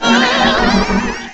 cry_not_mismagius.aif